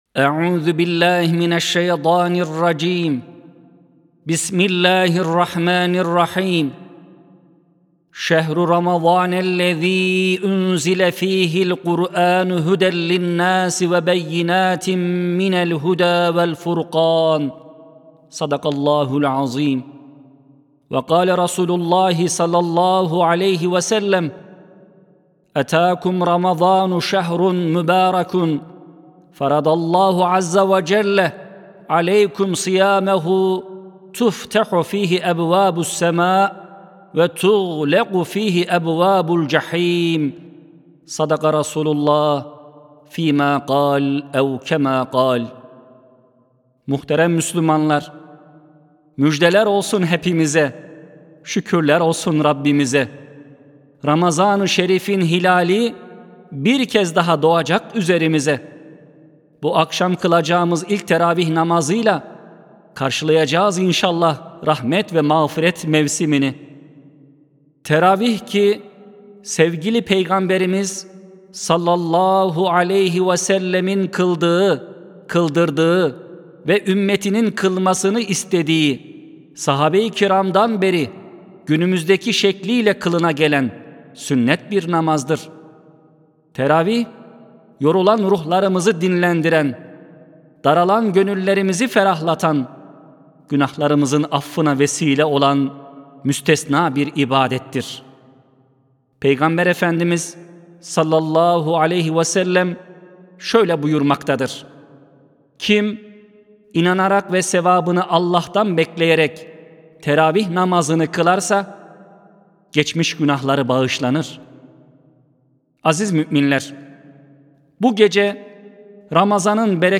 Sesli Hutbe (Rahmet ve Mağfiret Mevsimine Girerken).mp3